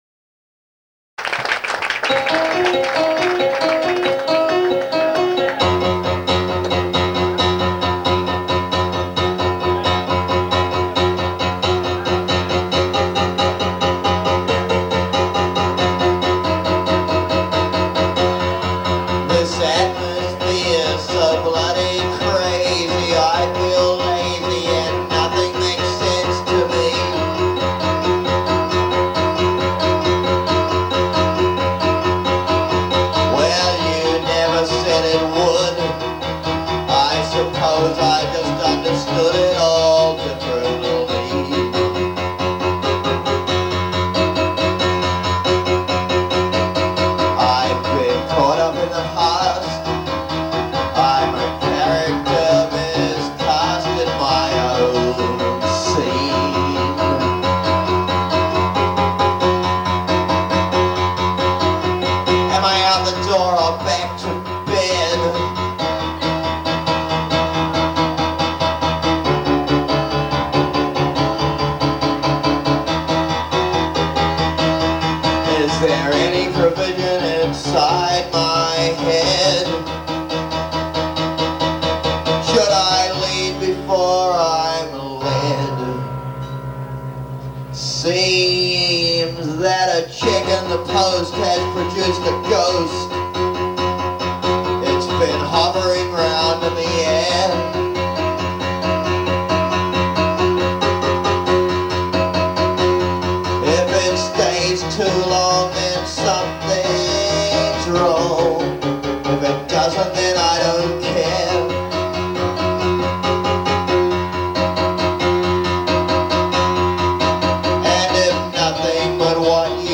This was recorded in the audience at the Khyber on 8/19/93.